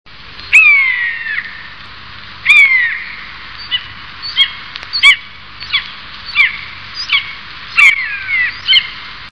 Myszołów - Buteo buteo
głosy